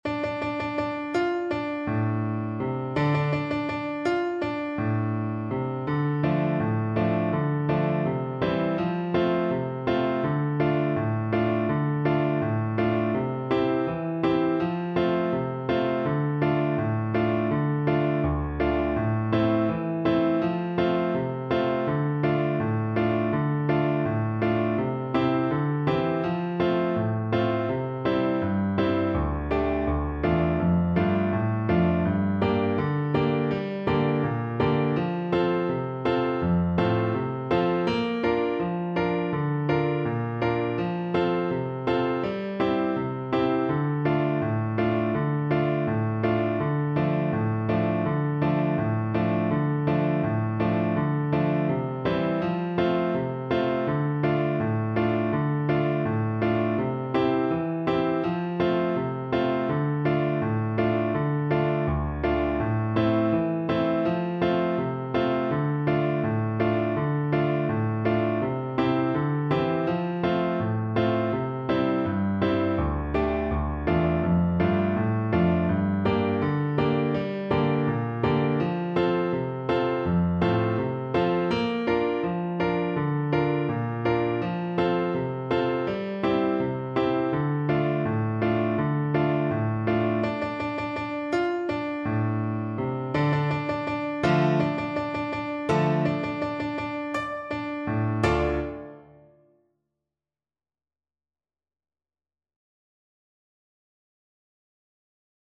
Allegro =c.110 (View more music marked Allegro)
4/4 (View more 4/4 Music)
Traditional (View more Traditional Clarinet Music)
world (View more world Clarinet Music)
Chinese